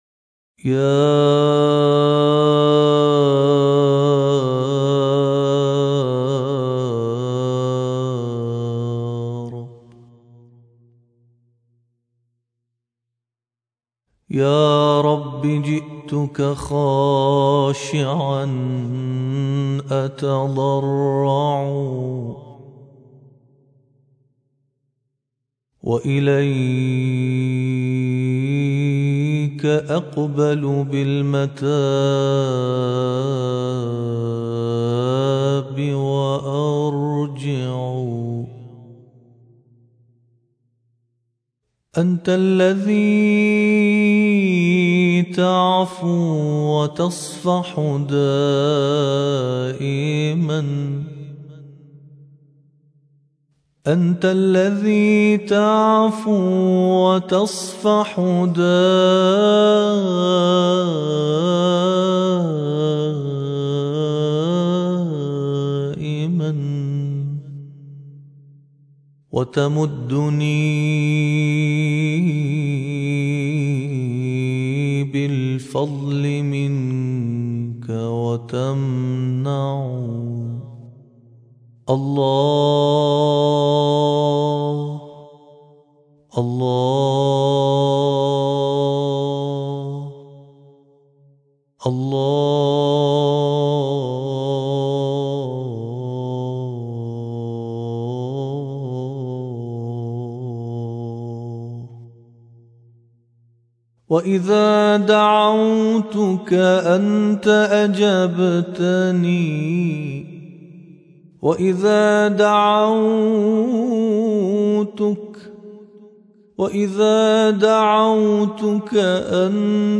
ابتهال